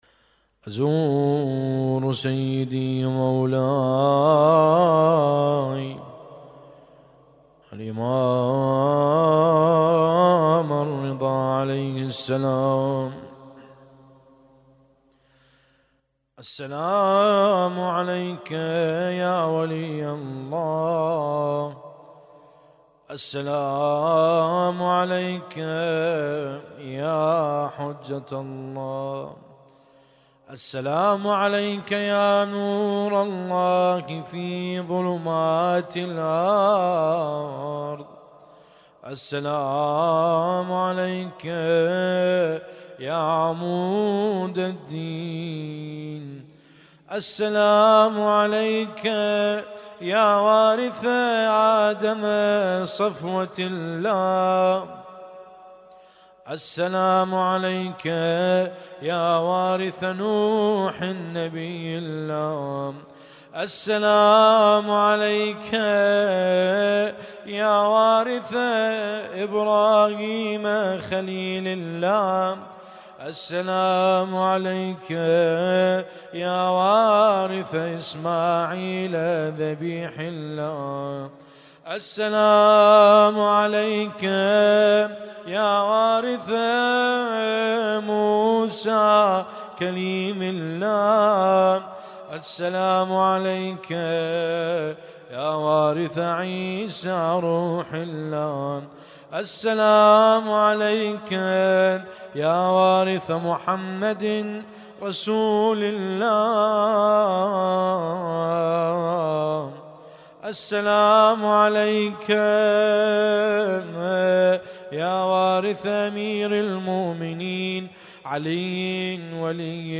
حسينية النور